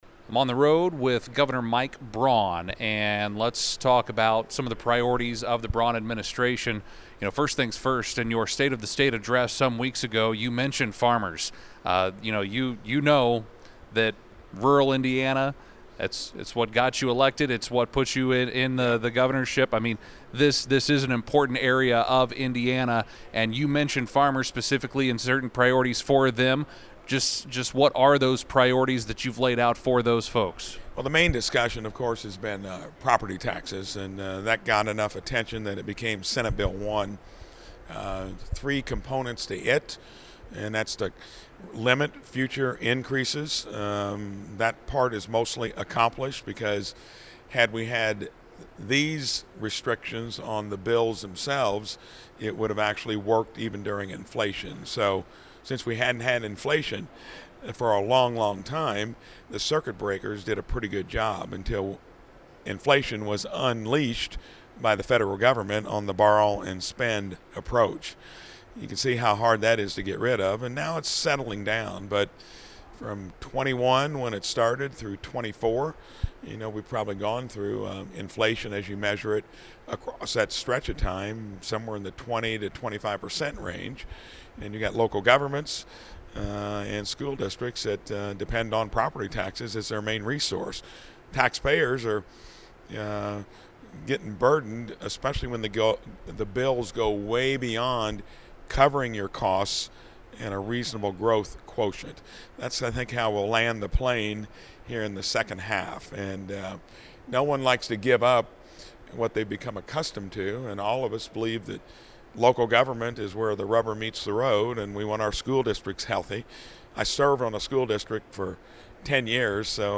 Governor Mike Braun was the featured guest at the Taste of Shelby County Agriculture event last week.